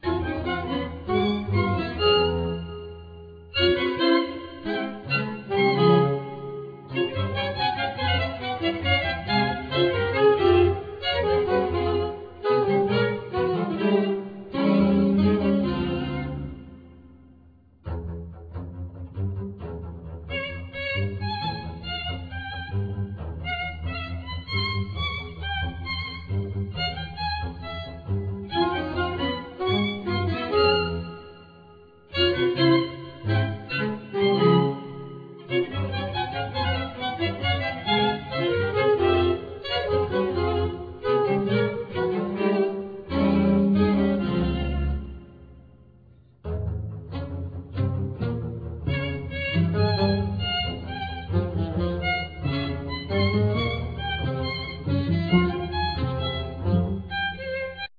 Soprano & Alto sax,Flute, Sampler
Violin
Cello
Double bass
Piano